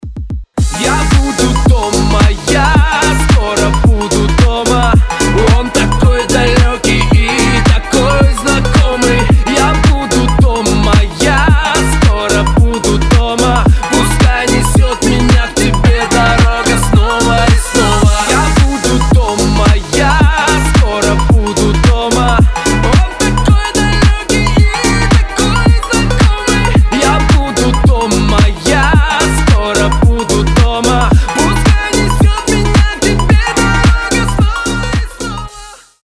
душевные